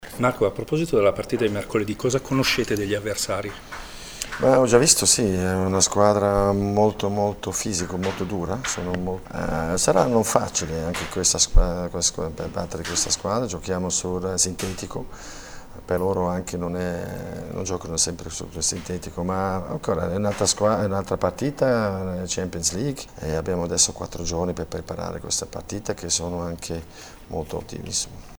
Nell’intervista che mi e’ stata concess sabato scorso, Marco Schallibaum ha dichiarato di non conoscere molto bene l’avversario e si aspetta una partita sicuramente dura a livello fisico: